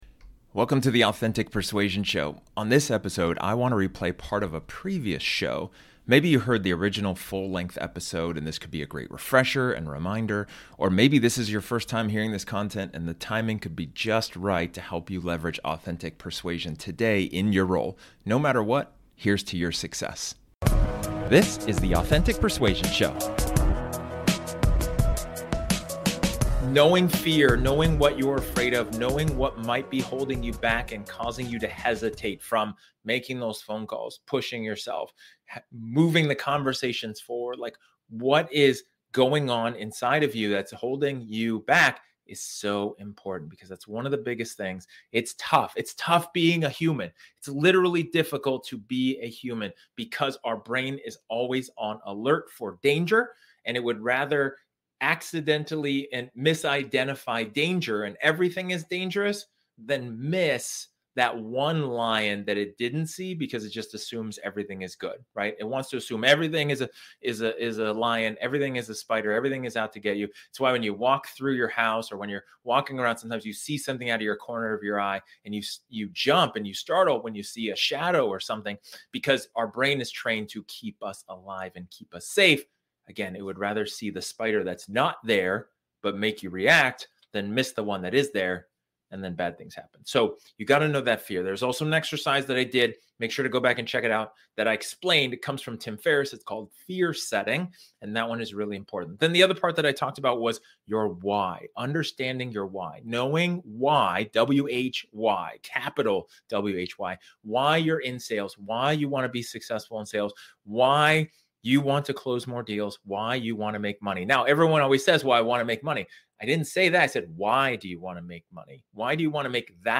In this episode, This episode is an excerpt from one of my training sessions where I talk about the importance of acknowledging your strengths.